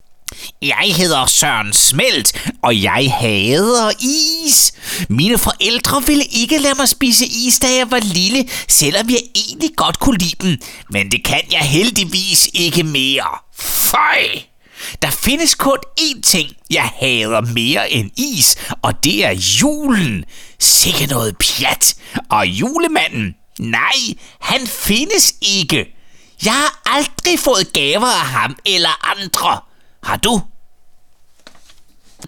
I am a male speaker living in Denmark.
Sprechprobe: Industrie (Muttersprache):
Danish voice over talent.